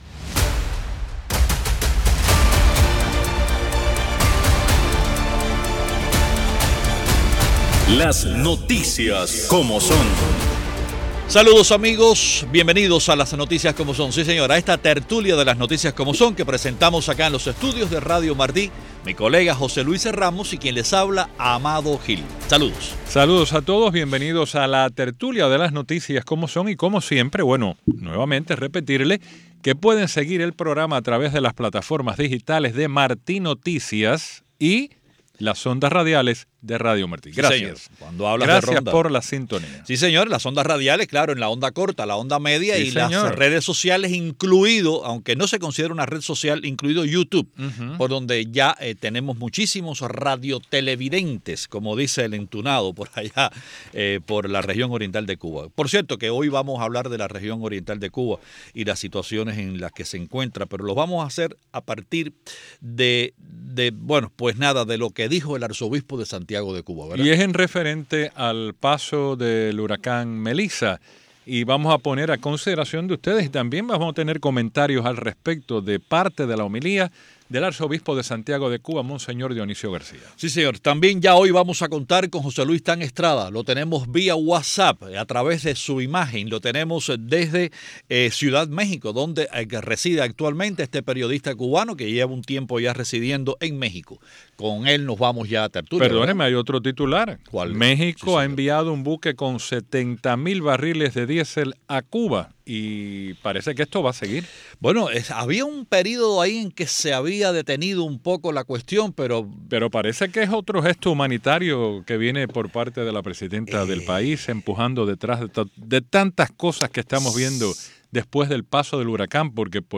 en tertulia